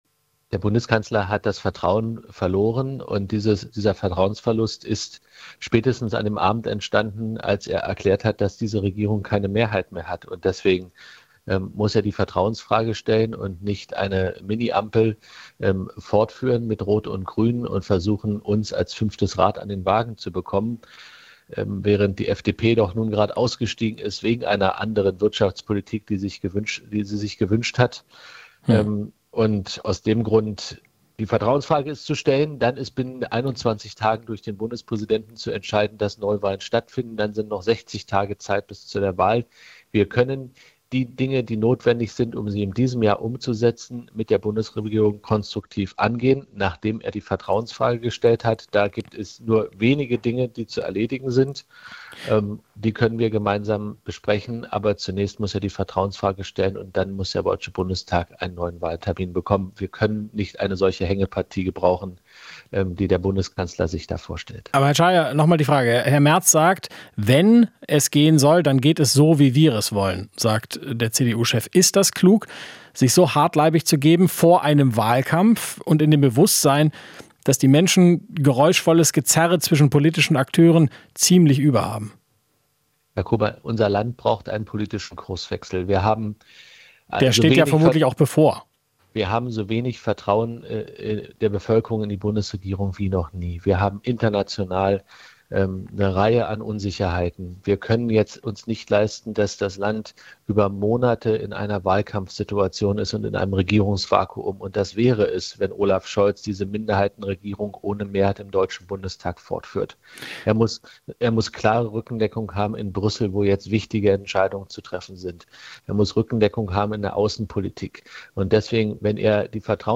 Interview - Czaja (CDU) zu Neuwahlen: "Können keine Hängepartie gebrauchen"